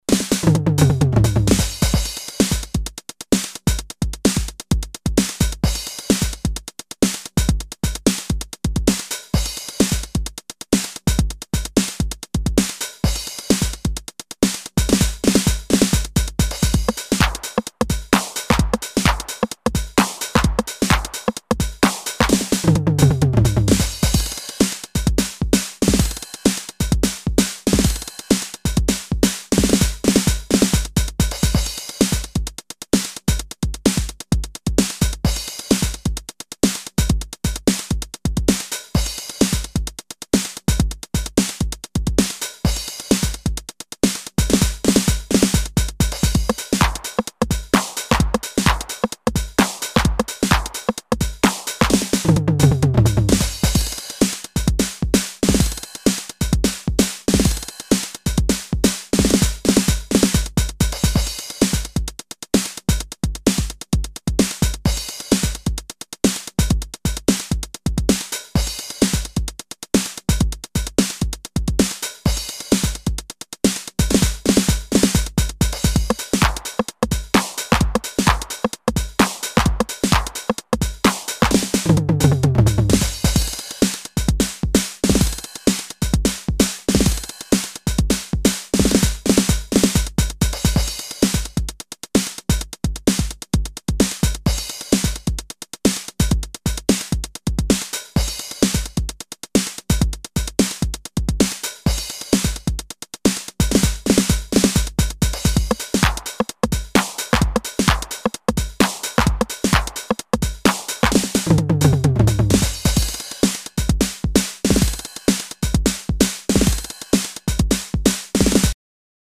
Rhythm Composer (1983)
demoAUDIO DEMO
factory pattern
demo jampattern
demo broken toms :)
demo session with ELKA SYNTHEX and TB303